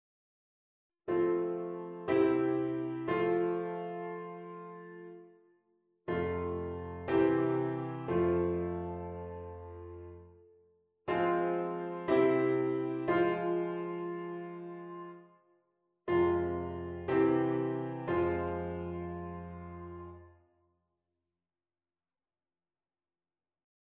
b. 5^ 7^ 1^ en 1^ 2^ 1^ . beide ook geschikt voor afsluitingen. de toon die de eerste twee akkoorden gemeenschappelijk hebben, blijft niet liggen.
onvolledig akkoord aan het eind